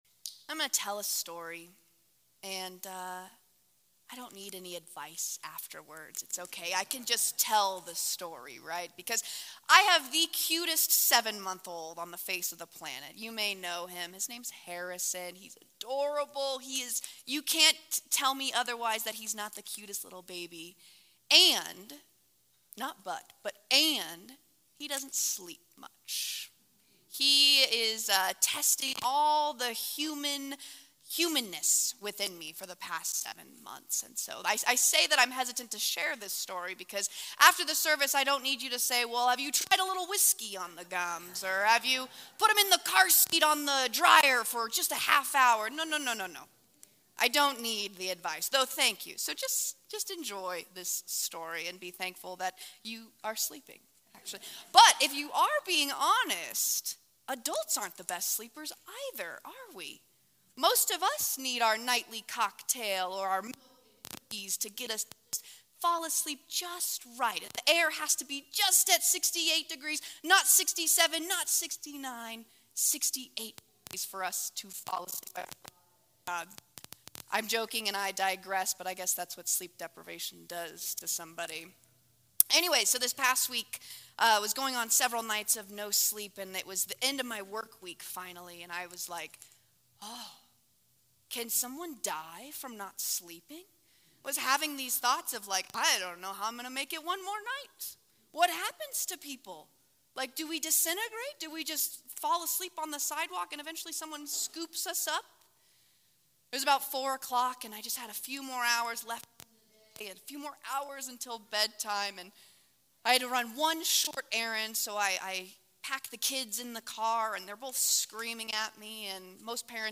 Drawing on Psalm 89’s themes of God's faithfulness and covenant love, this sermon discusses the liberating power of self-forgetfulness.